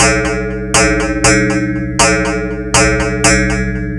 Metallic Kit.wav